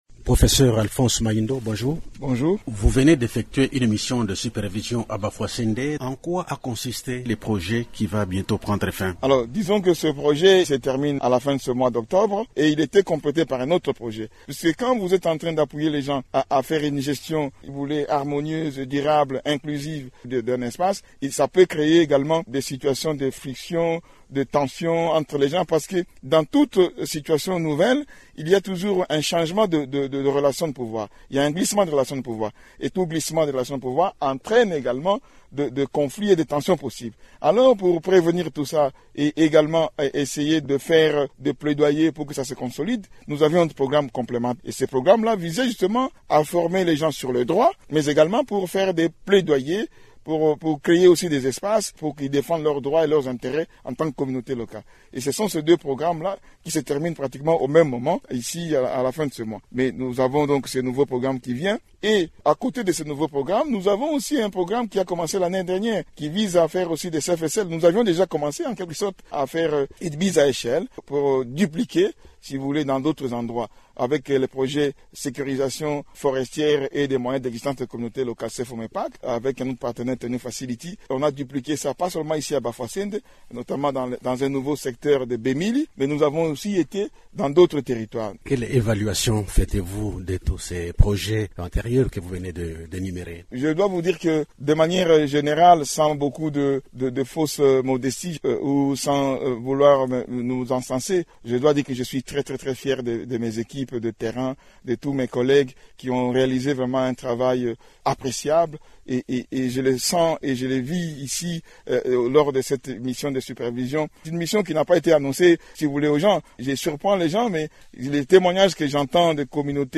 Société, Actualité, L'invité du jour, Émissions / Radio Okapi, Nouvelle grille de programmes, auditeurs, jeunes